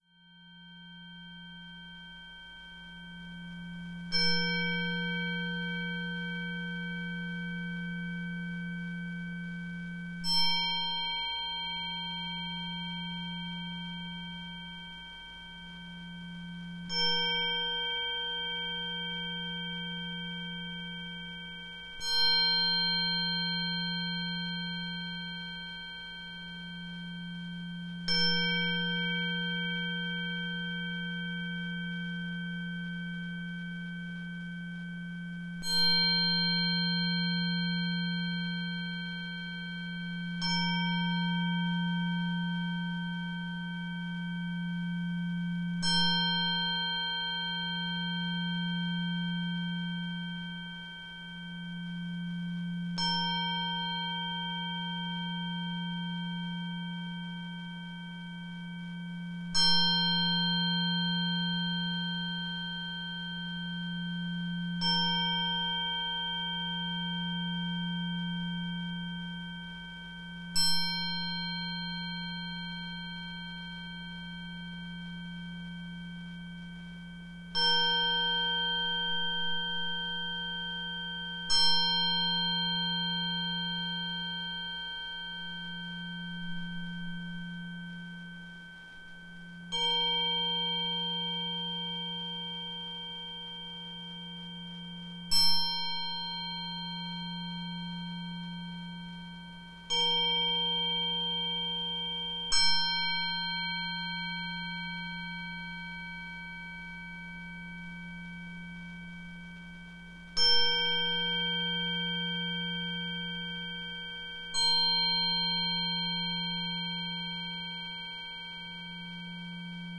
Scoprite il potere trasformativo delle meditazioni di guarigione con le frequenze, una miscela armoniosa di suoni e vibrazioni progettata per allineare mente, corpo e anima.
• 174hz